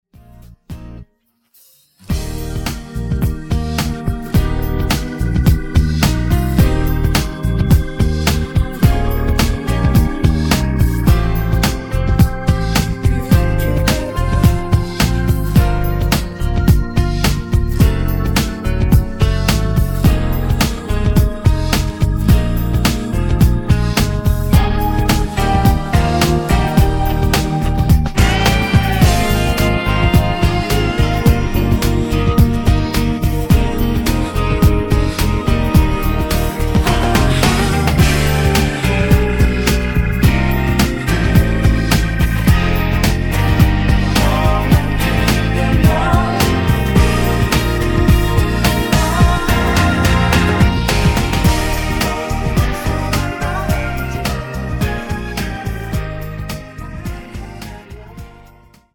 음정 원키 4:29
장르 가요 구분 Voice MR
보이스 MR은 가이드 보컬이 포함되어 있어 유용합니다.